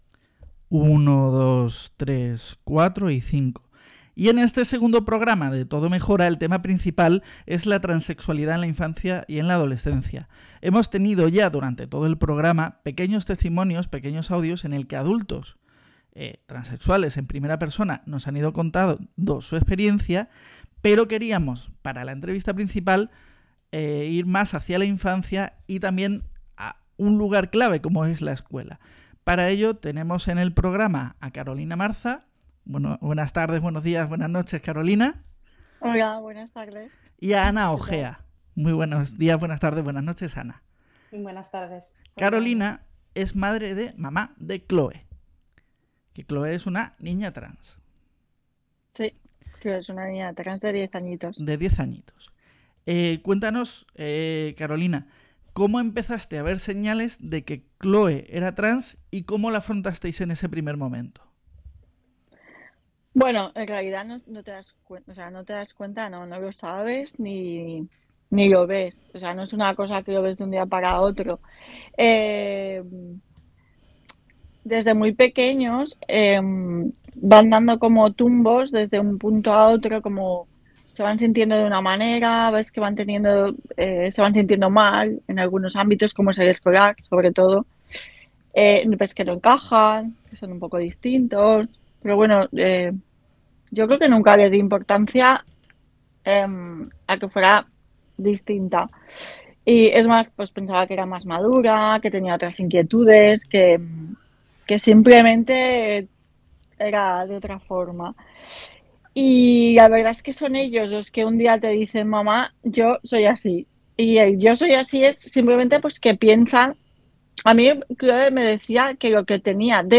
En el mes de la Visibilidad Trans, hemos dedicado el segundo episodio de nuestro podcast Todo Mejora a las infancias trans. Para ello, convocamos a dos mujeres con trayectorias muy diferentes, pero que nos ayudarán a comprender la realidad de nuestres menores en casa y en el cole.
Entrevista-Podcast-2-Web.mp3